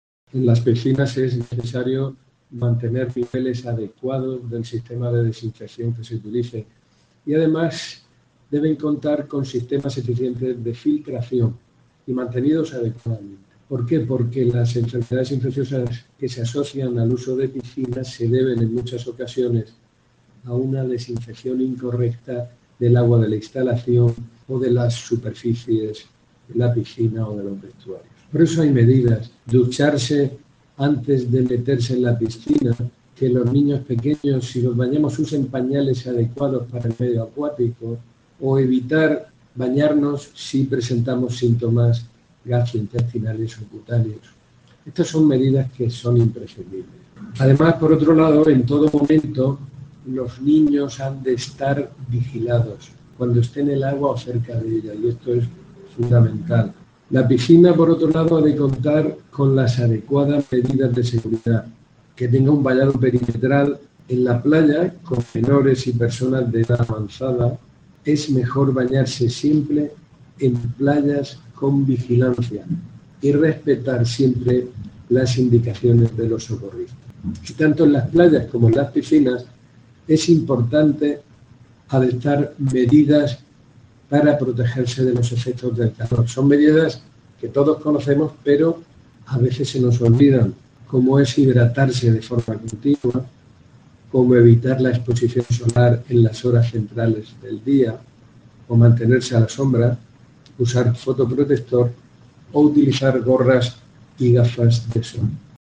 Declaraciones del director general de Salud Púbica y Adicciones, José Jesús Guillén, sobre el cuidado y prevención en las piscinas para garantizar una calidad del agua óptima para el baño y el cumplimiento de la normativa